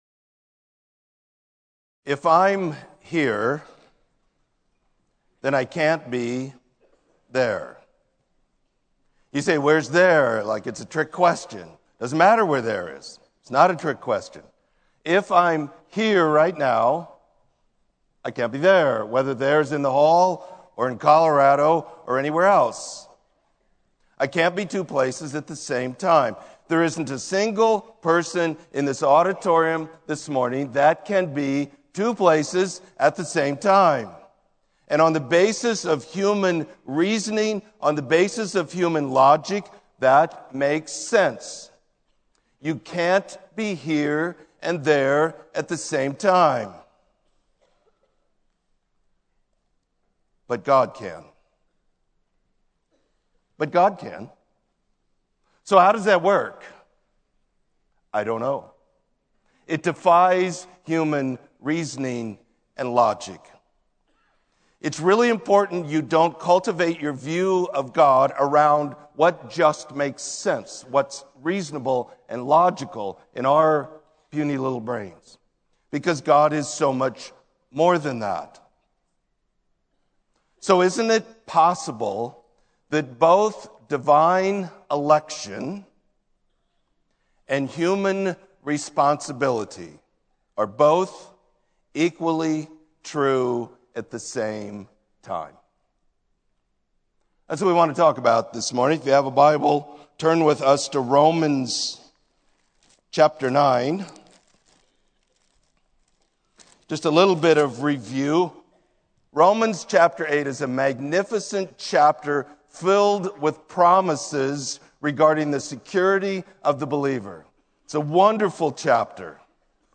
Sermon: Salvation by Faith